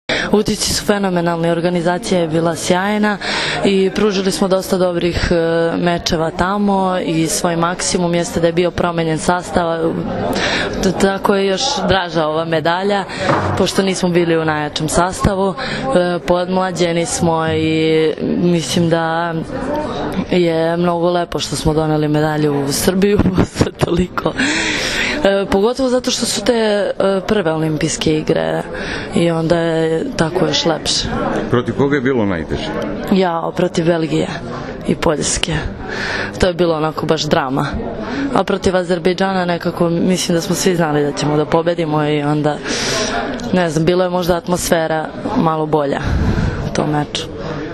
IZJAVA SLAĐANE MIRKOVIĆ